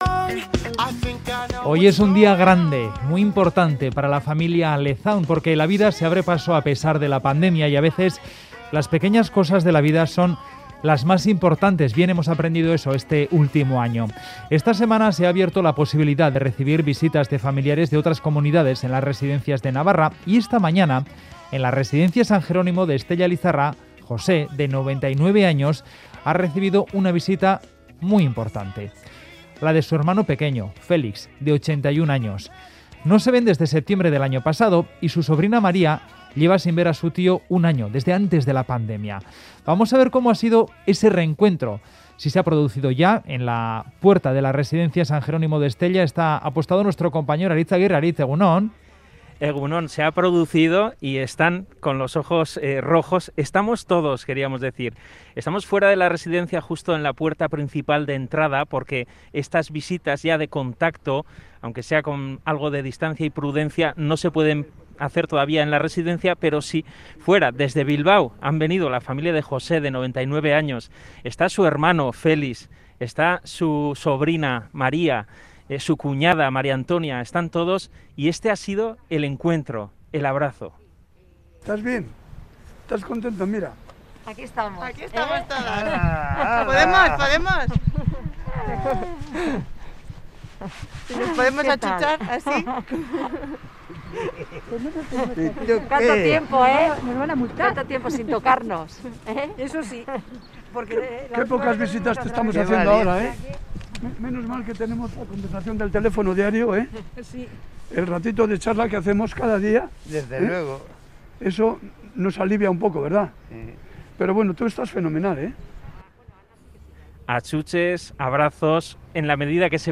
Audio: Visita de familiares en la residencia de Navarra